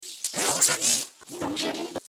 Un cri bien spécifique
Figurez-vous que, à partir de la forme de sa mâchoire et de son cou, les chercheurs sont parvenus à synthétiser ce qui serait le cri du Fredéjamyus !
Le résultat est un son assez perturbant, mais qui a bizarrement quelque chose de familier :
fredejamyus-cri.mp3